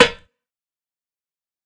Snares
Sn (NoType).wav